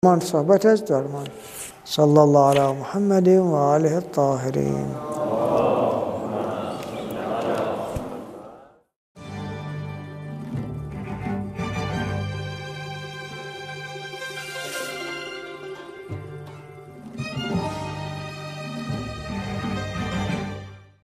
تدریس جلد 2 ، جلسه 17